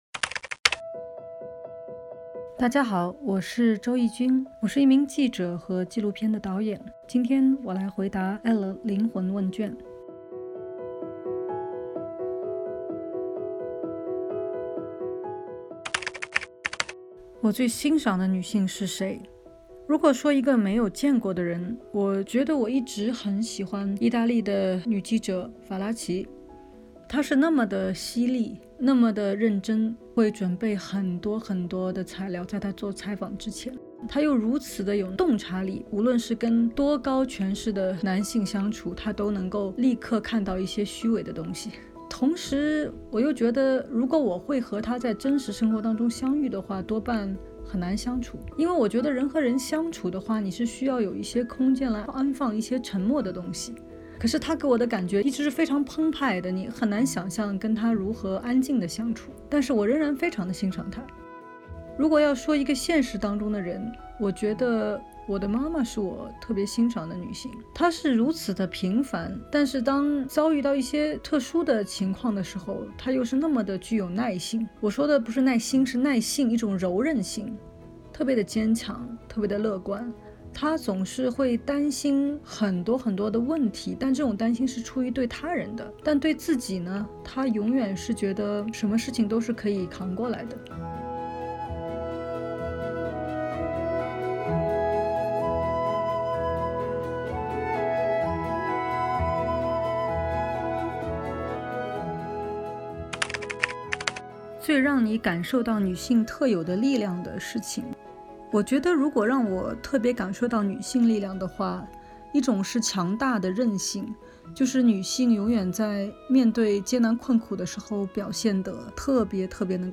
今天，为你带来答卷的是记者、纪录片导演周轶君。